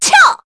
Veronica-Vox_Attack4_kr.wav